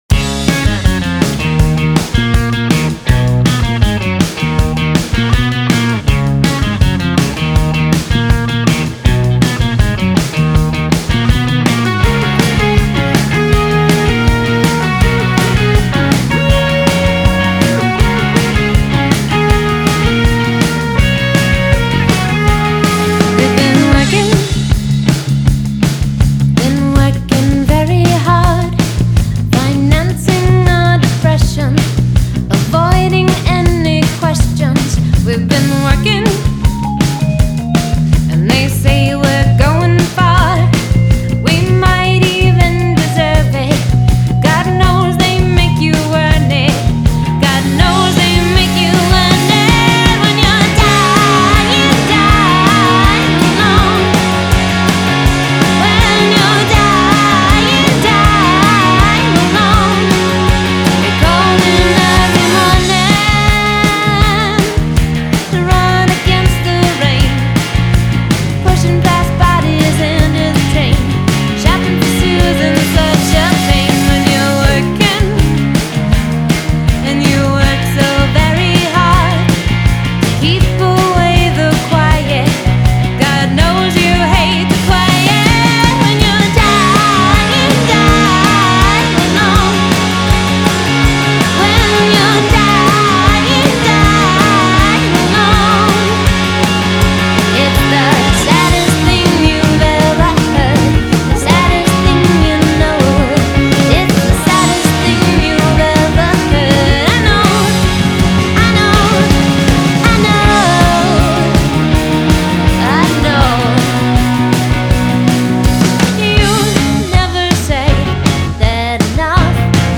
(Read: it’s new wave-y.)